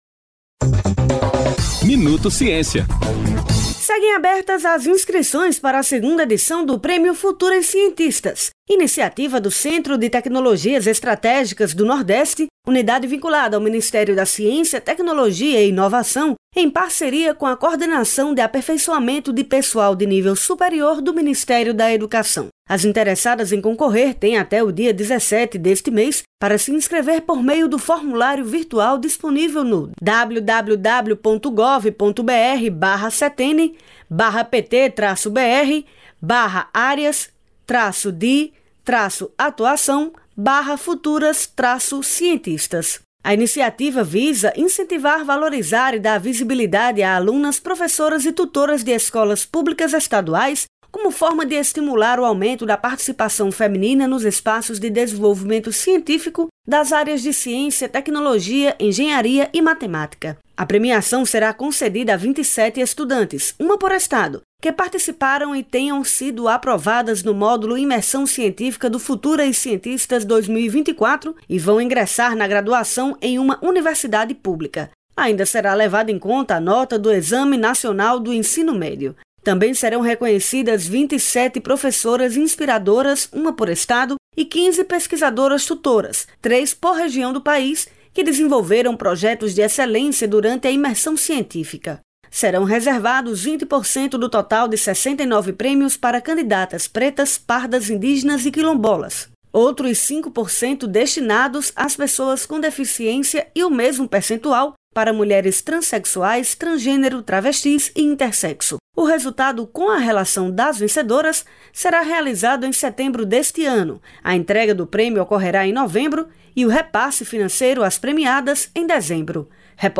Com aporte do FNDCT, Finep e BNDES, empresas terão R$ 5 bilhões para fomentar projetos de capacidade produtiva, pesquisa e inovação. Os detalhes com a repórter